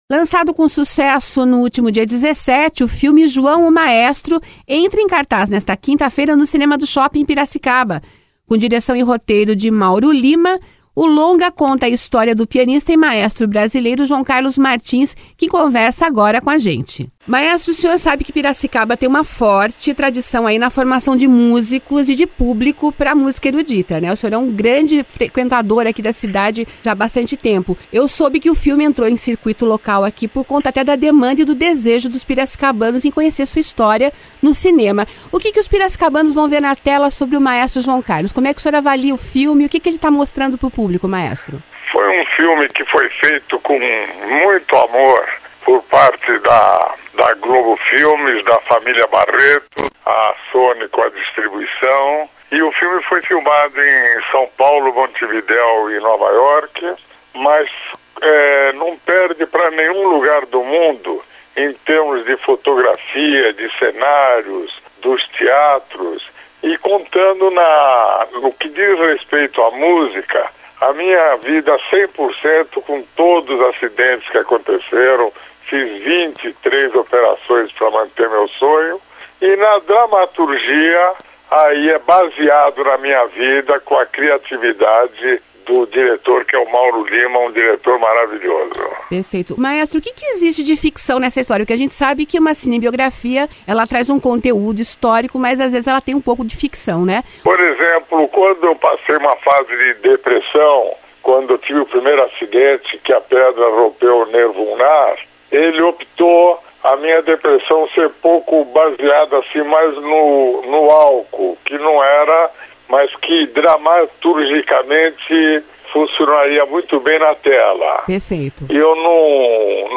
Entrevista
Acompanhe a entrevista com o maestro, em áudio: